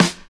43 SNARE 2.wav